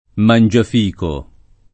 [ man J af & ko ]